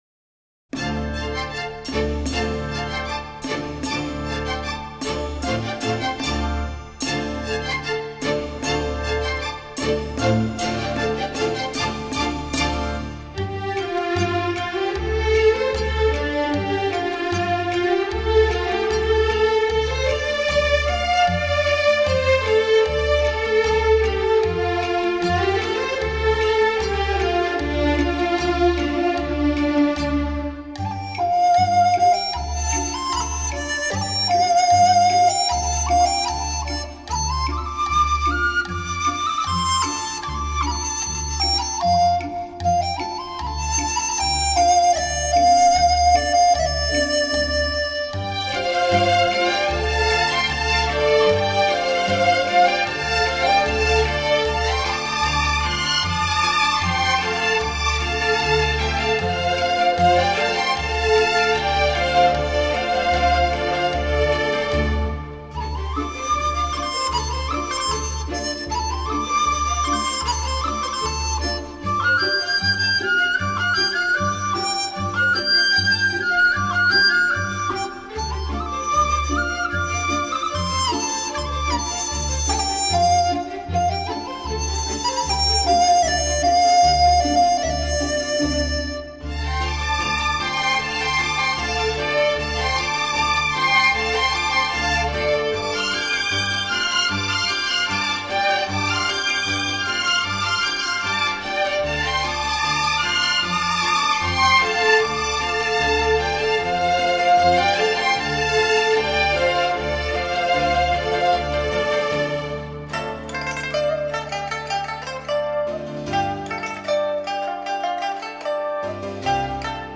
音乐类型：民乐
其小调形式规范、旋律婉丽、柔媚曲延。